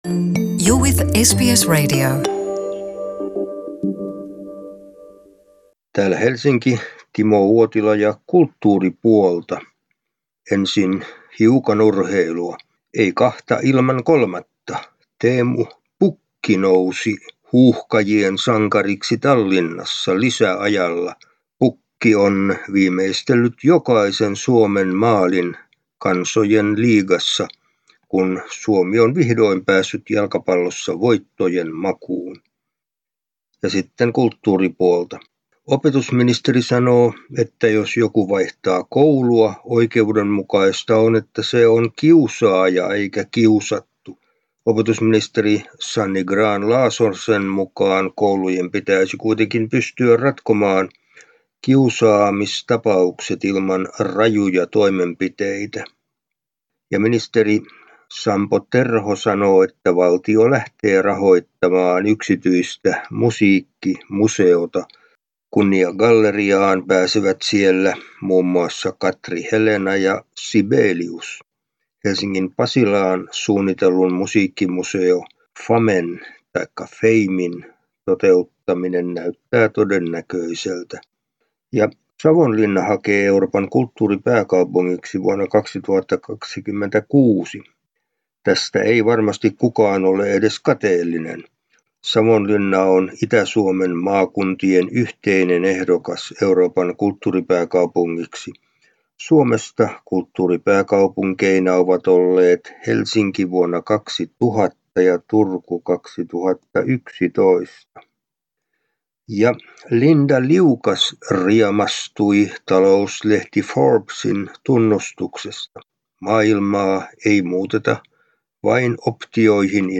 kulttuuriraportti Suomesta